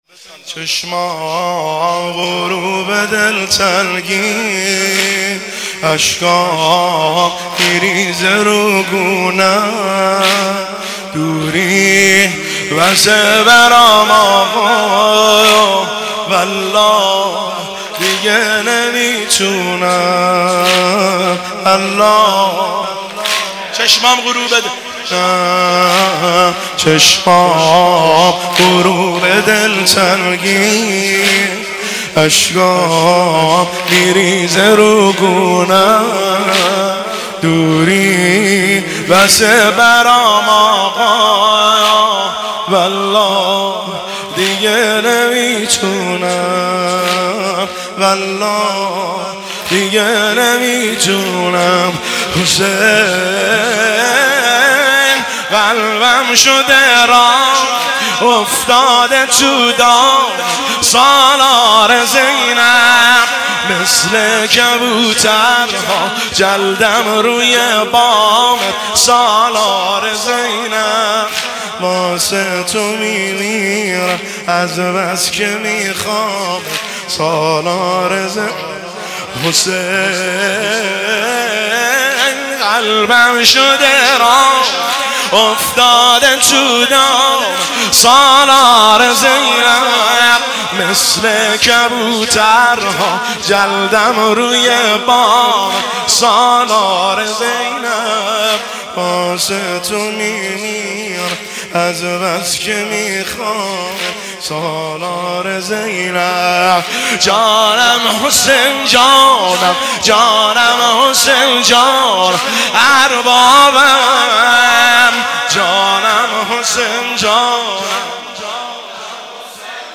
مداحی واحد
حسینیه چهارده معصوم شهر ری
فاطمیه دوم 1403